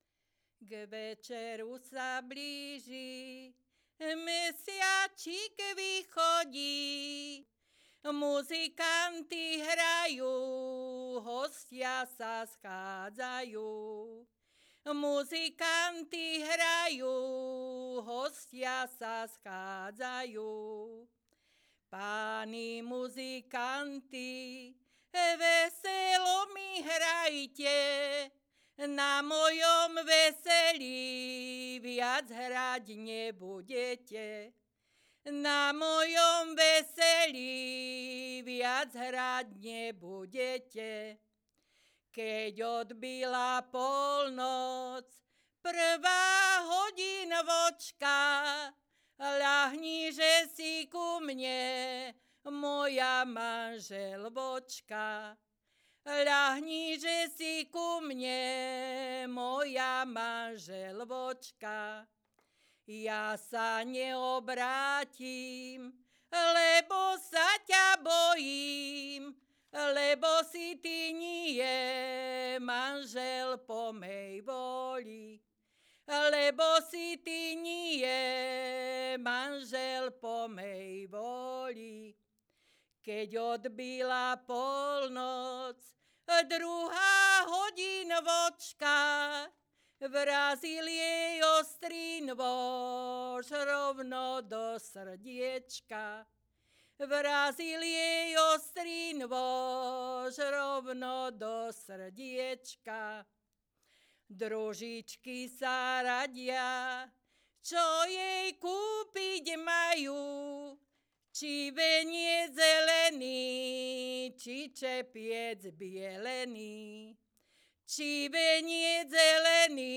Descripton sólo ženský spev bez hudobného sprievodu
Place of capture Brehy
Key words ľudová pieseň
svadobné piesne